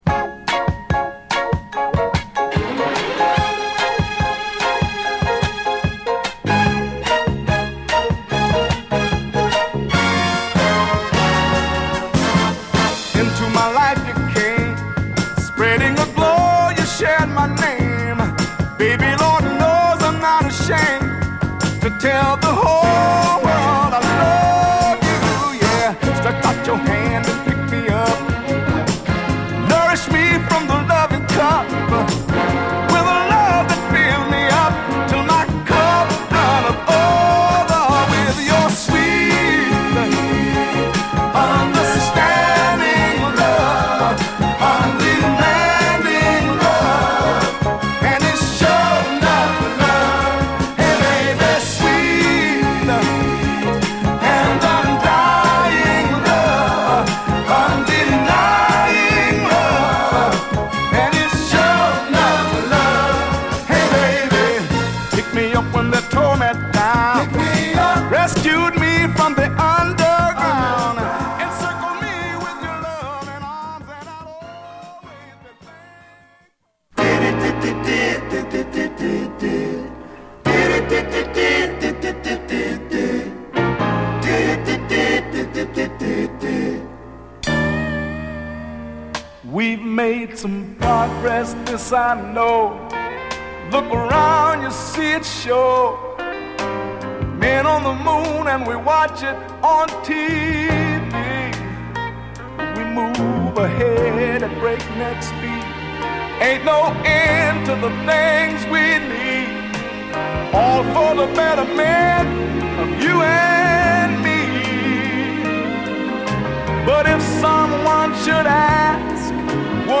キュートなピアノとストリングスが爽やかなイントロからはじまり、サビにかけて爽やかに盛り上がるポップなダンストラック！